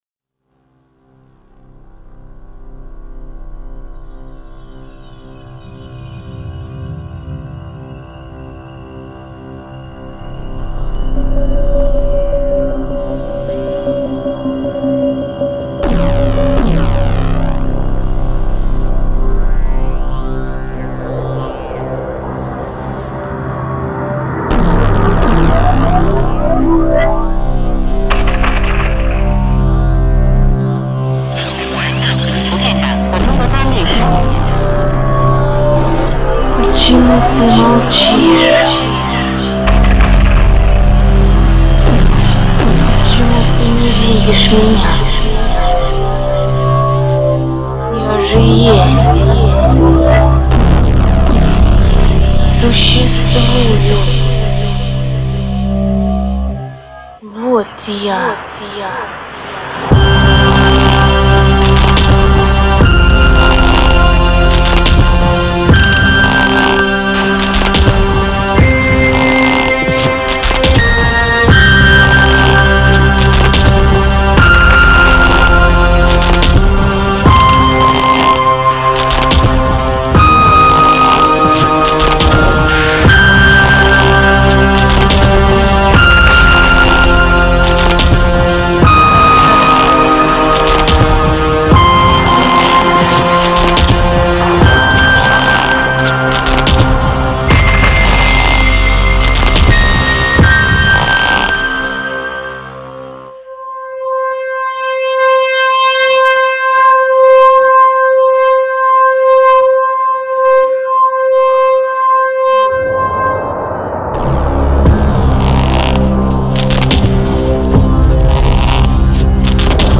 pure sensitive electronic music
Grinding sounds straight out from the boring hell of europe.